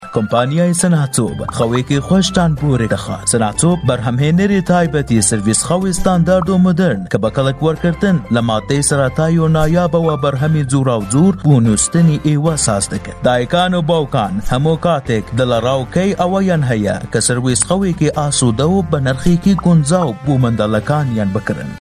Male
Young
Commercial